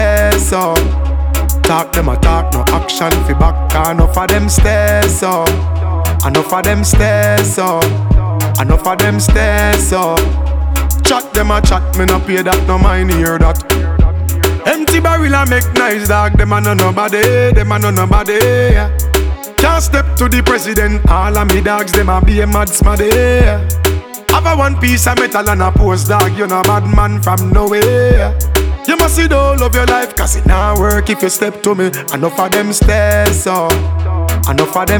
Жанр: Реггетон
# Reggae